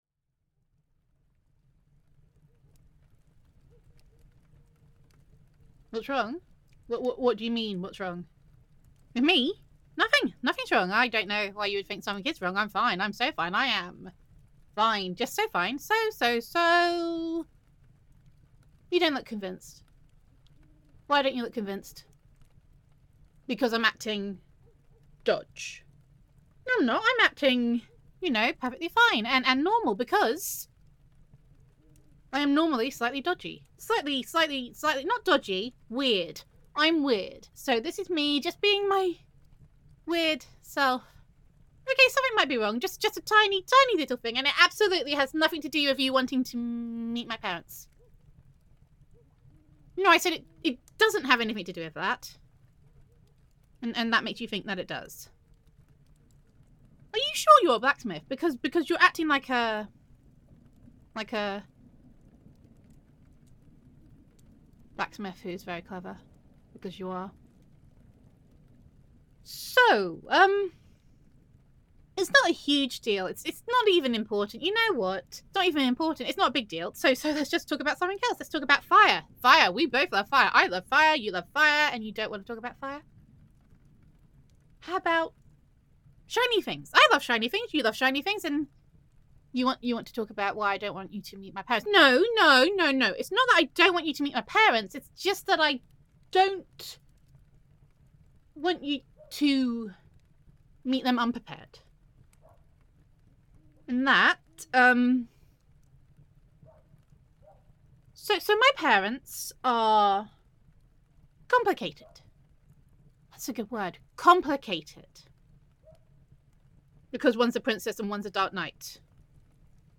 [F4A] Here Be Dragons [Fire Mage Roleplay][Girlfriend Roleplay][Blacksmith Listener][Utterly Ridiculous][Fantasy][Acting Super Dodge][Panic][Oooooh Fire][Oooooh Shiny][Infecting You with My Weirdness][Context Is Important][Gender Neutral][You Can’t Help but Get the Feeling That Your Girlfriend Does Not Want You to Meet Her Parents]